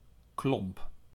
A klomp (Dutch: [klɔmp]
Nl-klomp.ogg.mp3